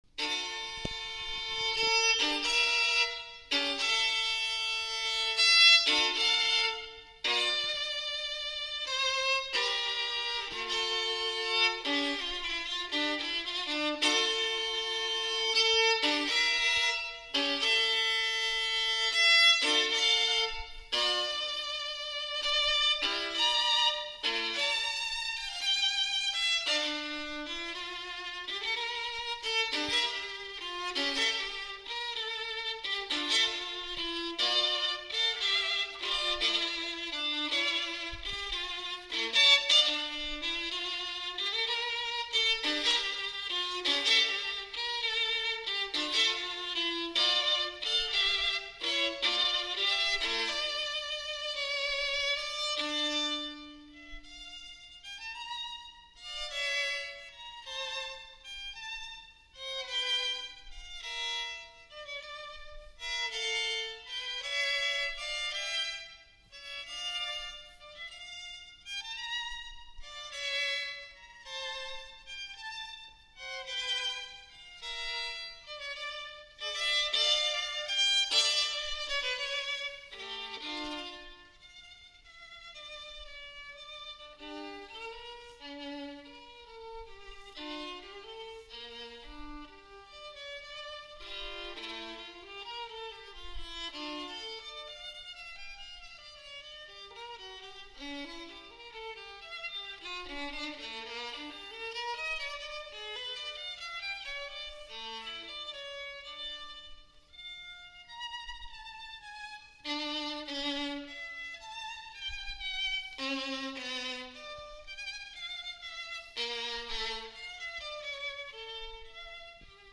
・JBL EVEREST 100 マイク録音：
ステレオICレコーダーによりリニアPCM無圧縮録音（44.1KHz/16bit）した.WAVファイルをSoundCloudに直接アップロードしています。
ちなみに、イヤホンの音を録音する時は、イヤホンの左右のドライバーをレコーダーの左右のマイクに押し当てて行いました。
chaconne-everest-1.mp3